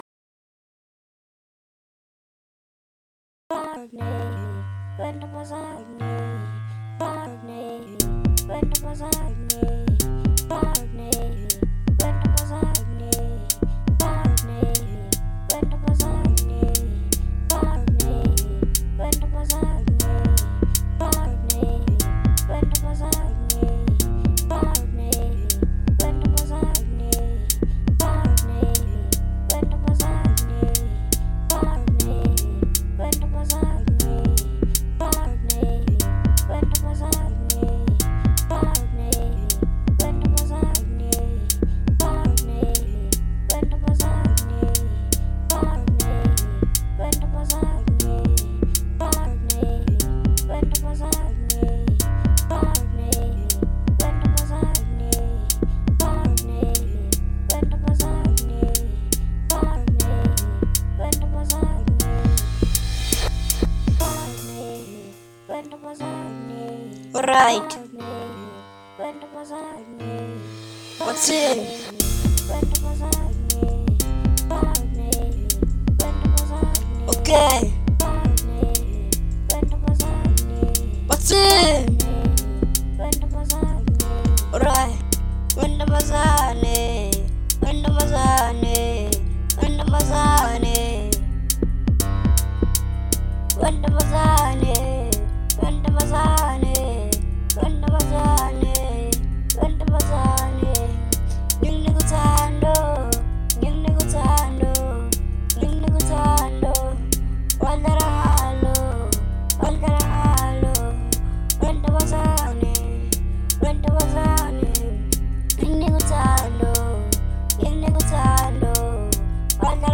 02:51 Genre : Amapiano Size